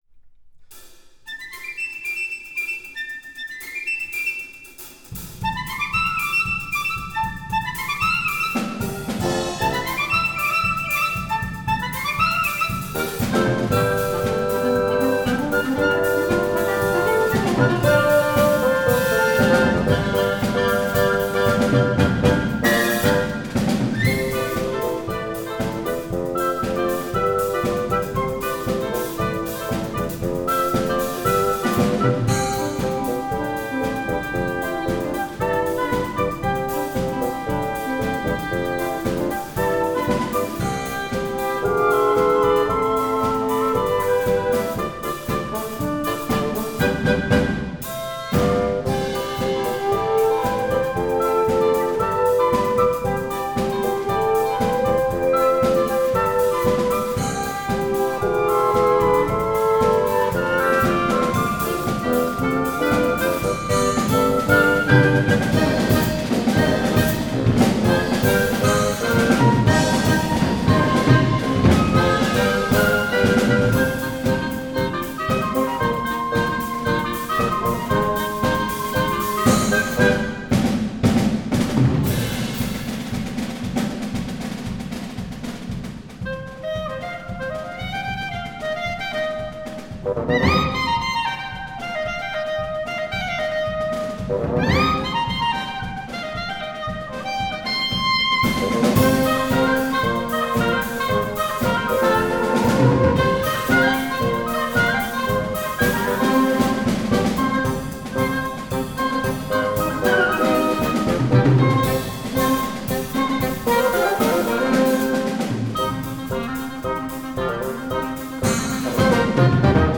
Gattung: für 5-stimmig flexibles Ensemble
Besetzung: Ensemble gemischt